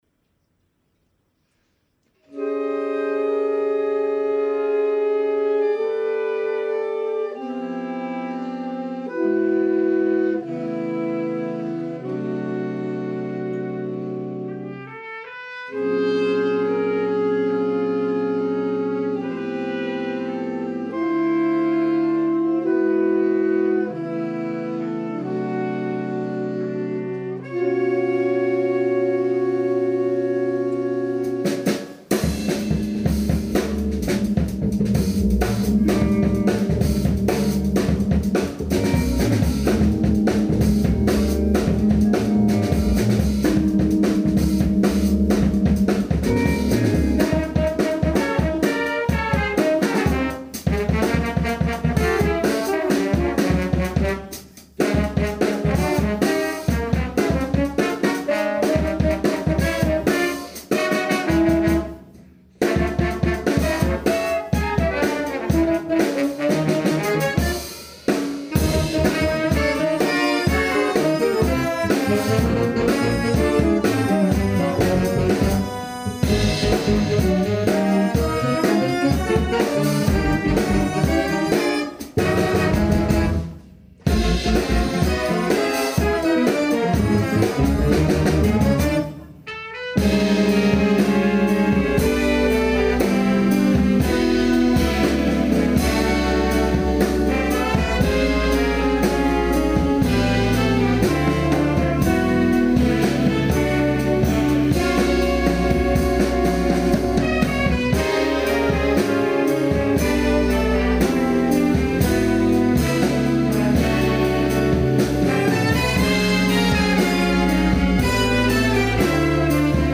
a blasting Groove-Piece with electric bass and keyboards
Solo for Trombone.